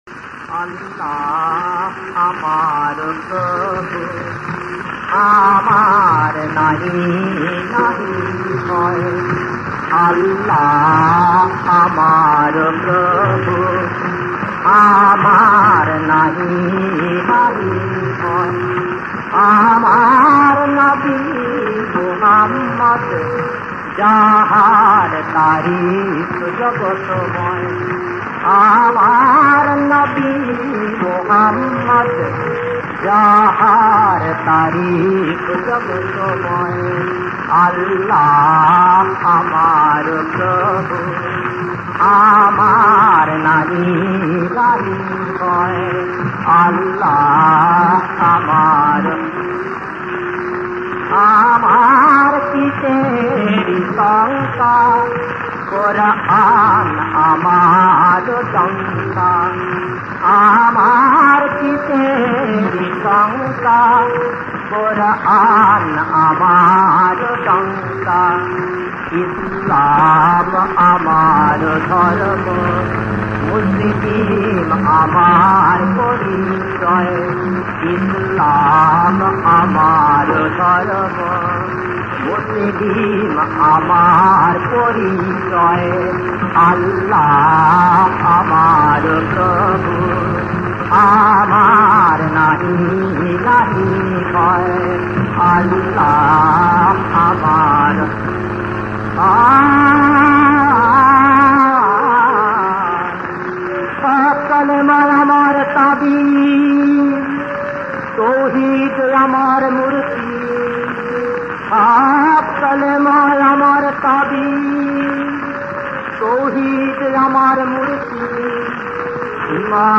• বিষয়াঙ্গ: ধর্মসঙ্গীত।
• সুরাঙ্গ: গজলাঙ্গ
• রাগ: ভৈরবী
• তাল: কাহারবা